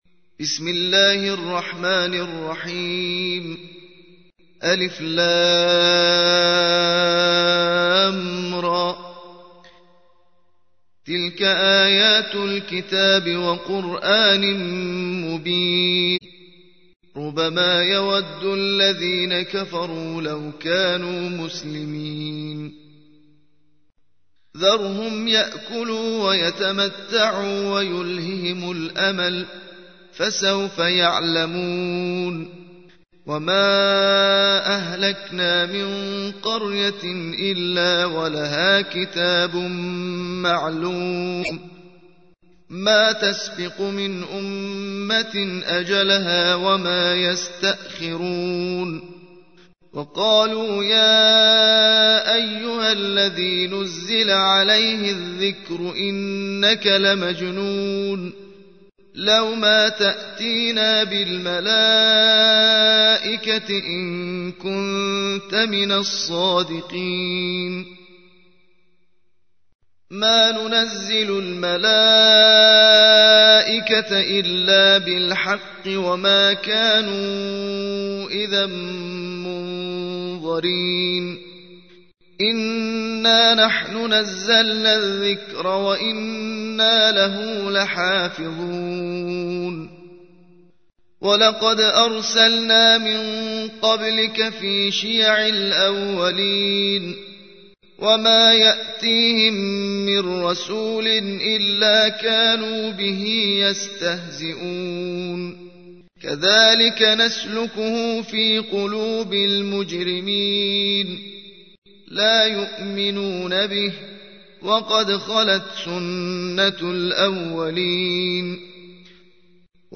15. سورة الحجر / القارئ